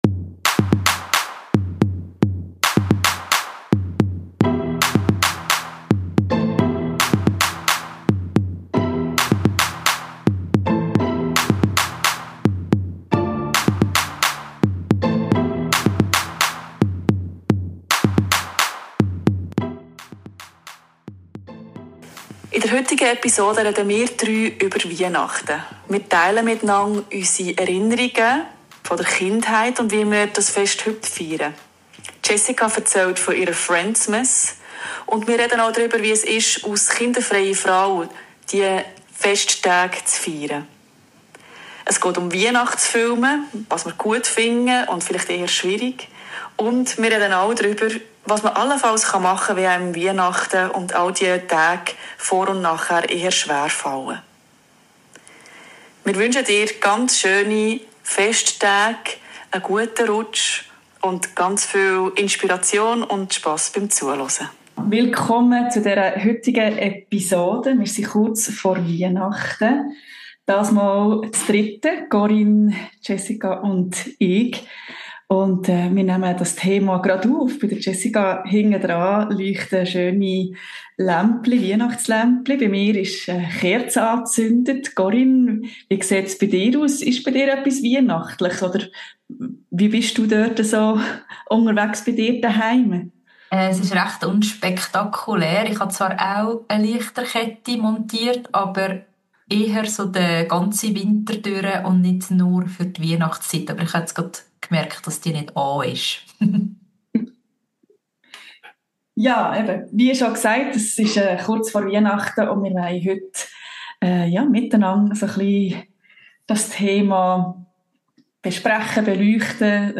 Beschreibung vor 3 Monaten In dieser Weihnachts-Episode sprechen wir zu dritt über ein Fest, das für viele von klein auf mit klaren Bildern, Ritualen und Erwartungen verbunden ist – und darüber, was Weihnachten für uns als kinderfreie Frauen bedeutet.